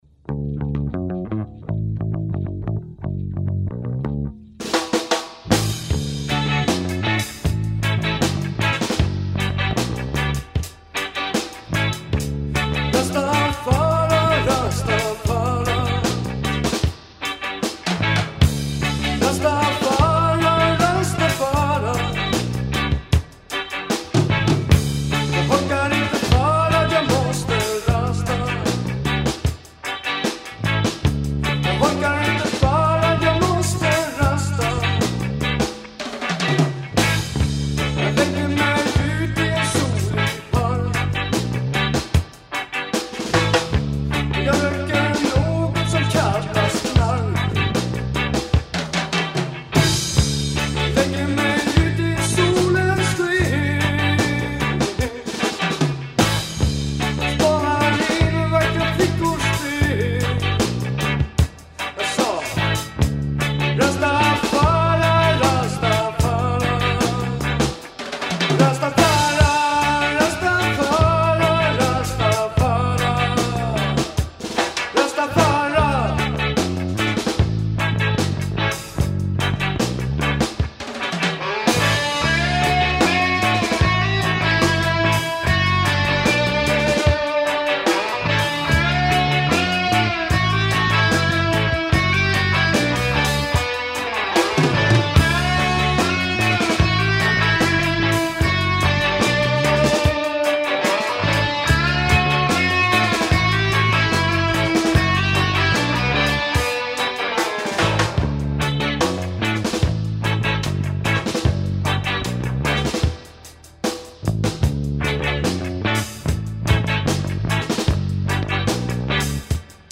Drums
Guitar, Voice
Bass